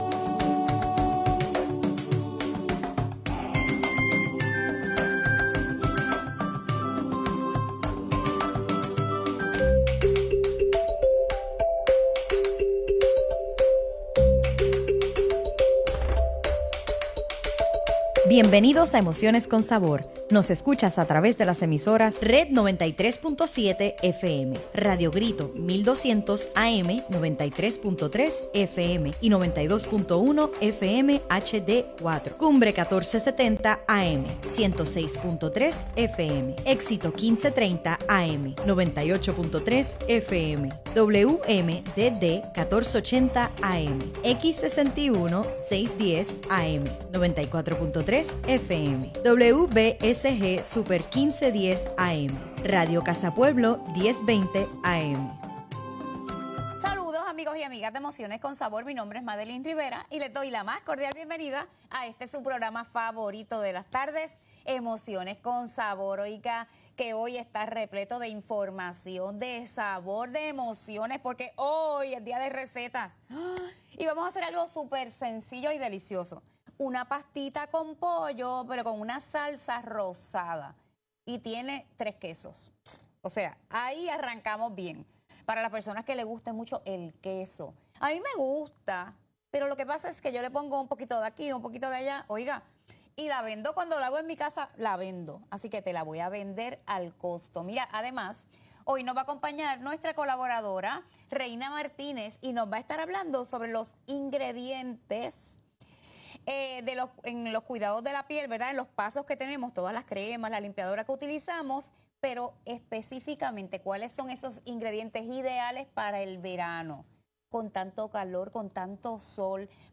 RADIO GRITO RED 93.7FM y live por Facebook